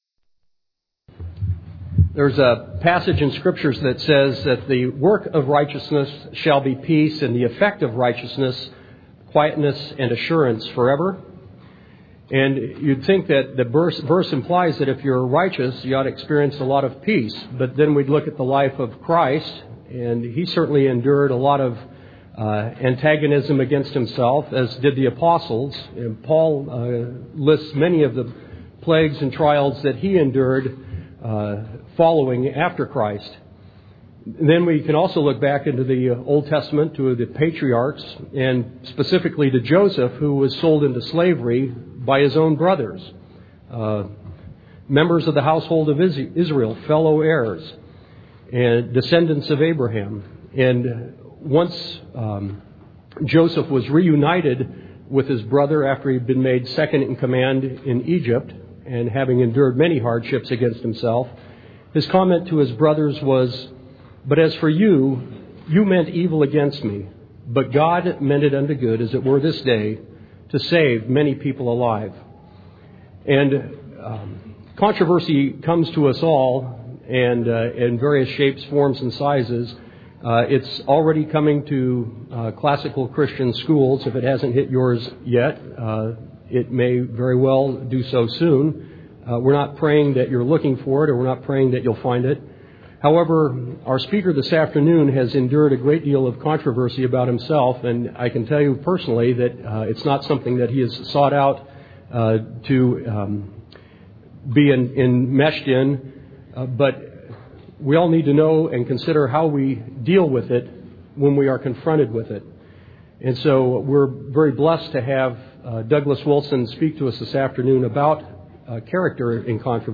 2004 Plenary Talk | 0:50:27 | Culture & Faith